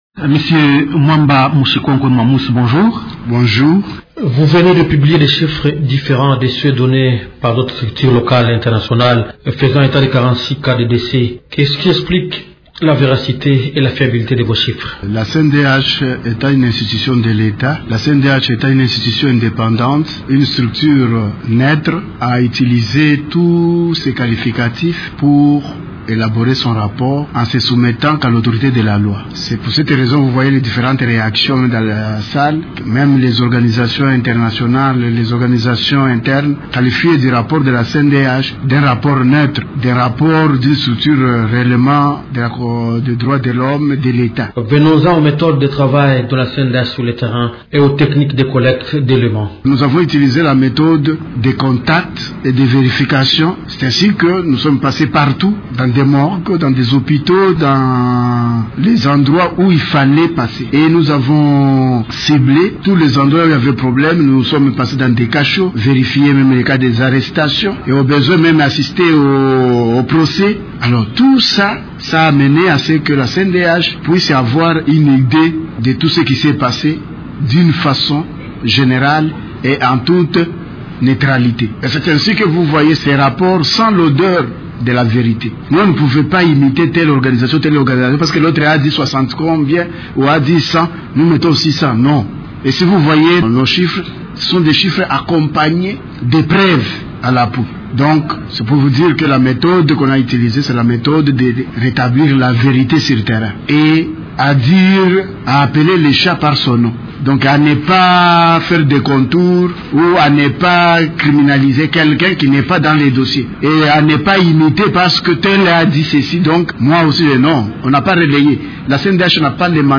Invité de Radio Okapi vendredi 9 décembre, le président de la Commission nationale des droits de l’homme (CNDH), Mwamba Mushikonkwe Mwamus, revient sur le rapport ponctuel publié par son institution sur la situation des droits de l’homme consécutivement aux manifestations publiques du 19 et 20 Septembre 2016 à Kinshasa.